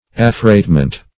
Search Result for " affreightment" : The Collaborative International Dictionary of English v.0.48: Affreightment \Af*freight"ment\, n. [Cf. F. affr['e]tement.] The act of hiring, or the contract for the use of, a vessel, or some part of it, to convey cargo.